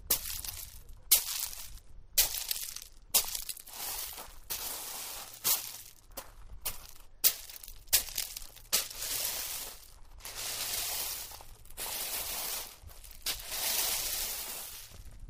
Hayling Island - footsteps on a stone beach
Recorded outside RNLI Hayling Island, summer 2012.